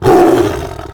combat / creatures / tiger / he / hurt2.ogg
hurt2.ogg